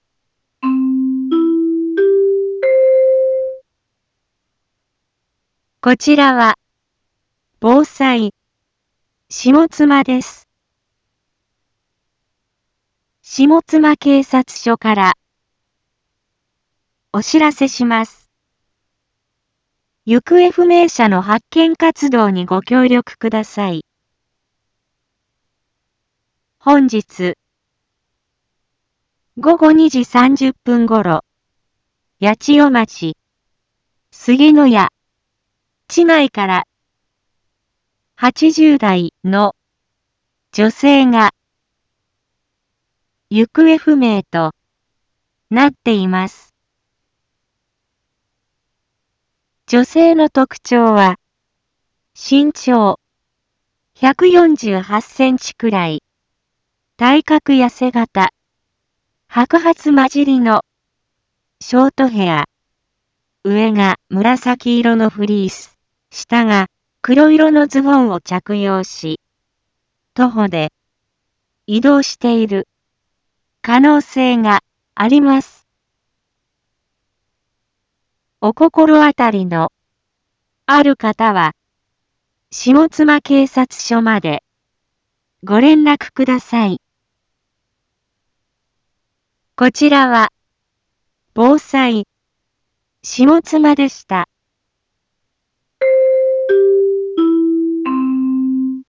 一般放送情報
Back Home 一般放送情報 音声放送 再生 一般放送情報 登録日時：2025-03-14 18:19:58 タイトル：行方不明者情報について インフォメーション：こちらは、防災、下妻です。